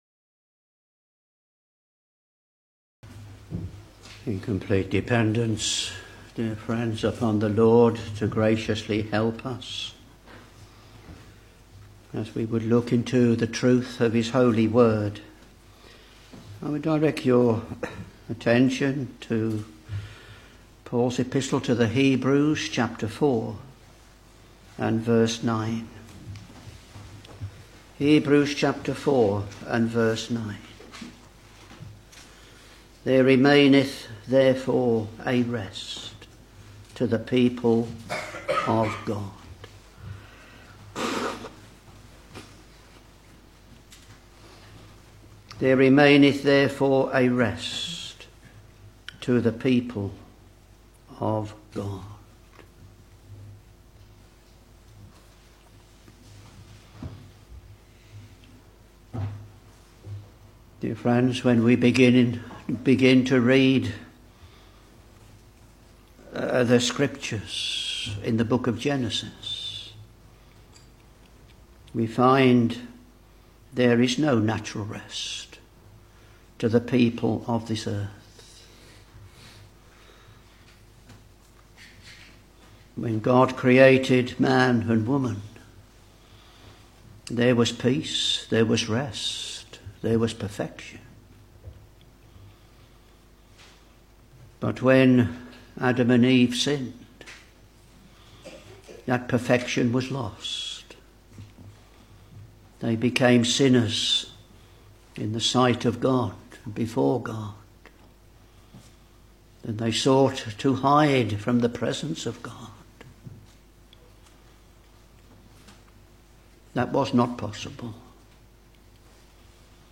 Back to Sermons Hebrews Ch.4 v.9 There remaineth therefore a rest to the people of God.